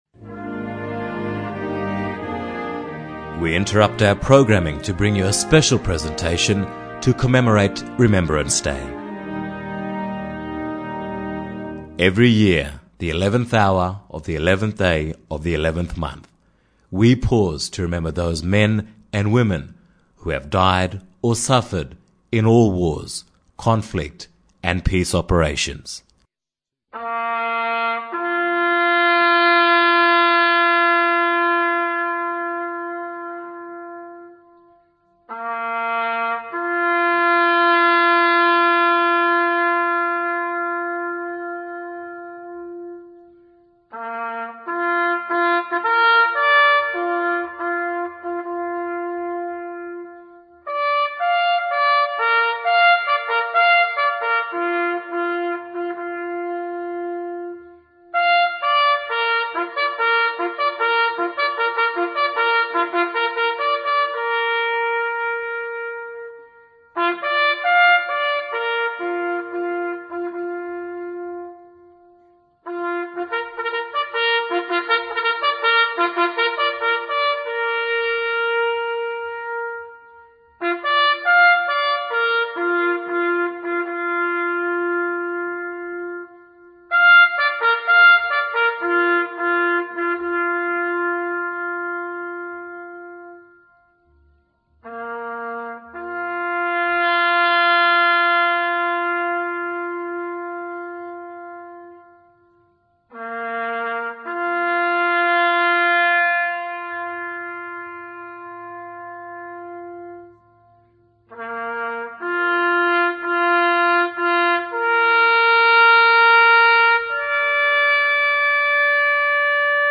Listeners to Black Star Radio network stations in remote Queensland will be able to pay their respects to fallen Australian soldiers in all wars by hearing the last post in their communities. Every radio station will air this remembrance and the minute silence at 11am.